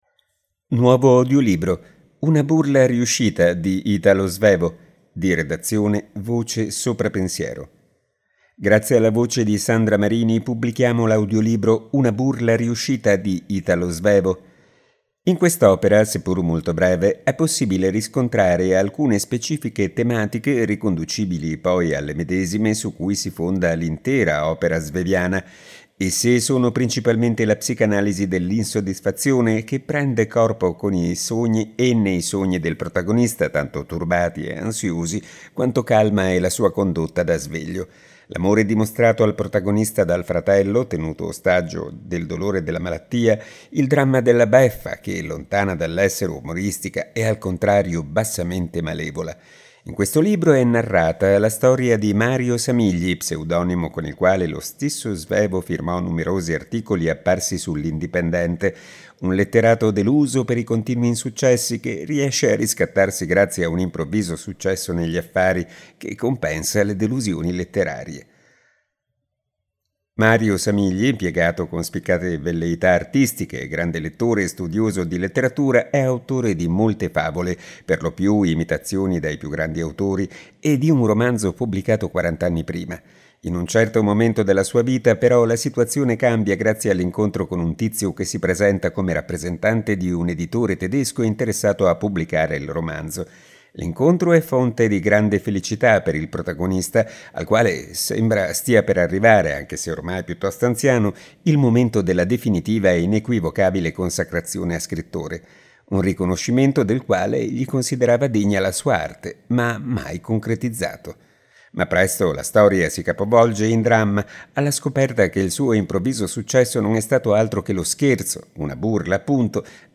Nuovo audiolibro. “Una burla riuscita” di Italo Svevo | Pagina Tre